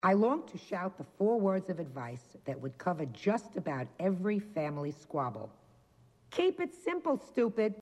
AUDIO BOOKS
Daytime judge heard
here throwing the audio book at someone